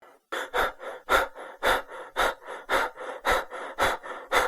Horror Breaths
Horror Breaths is a free horror sound effect available for download in MP3 format.
yt_DNXB_8X2cJw_horror_breaths.mp3